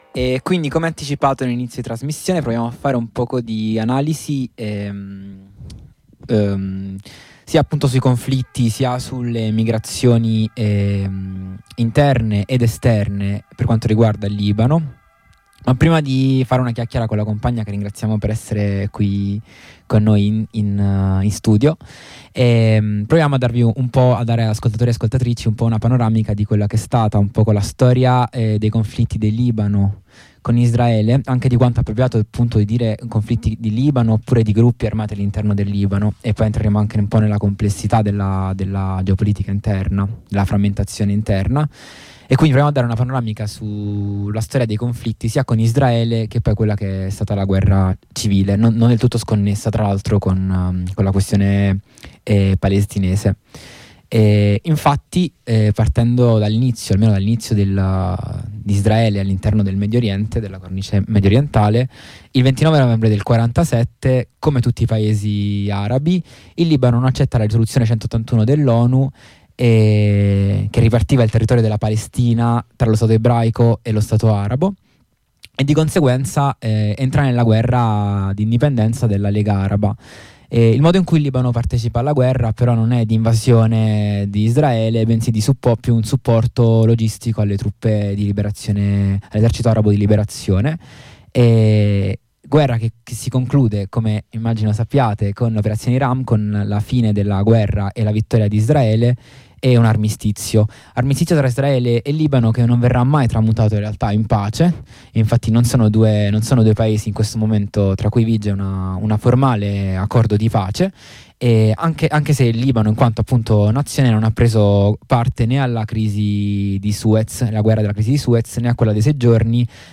In questa puntata di Harraga, in onda su Radio Blackout proviamo a restituire la complessità del contesto libanese grazie al contributo di una compagna che da anni vive a Beirut.